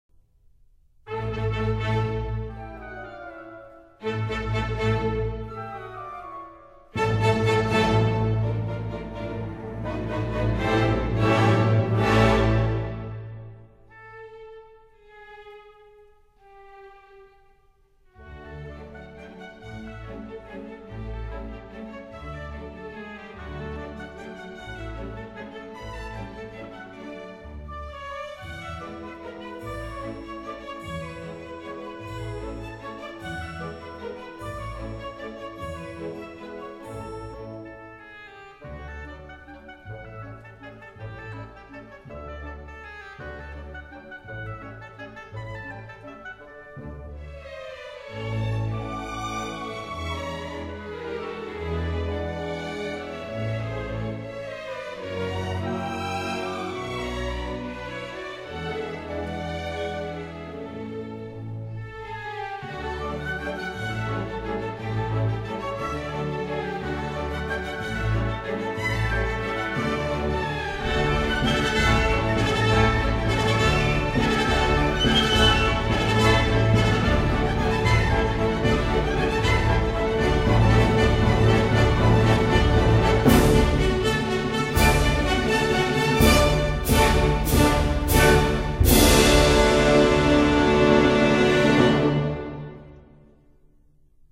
Valse
华尔兹 <01:34>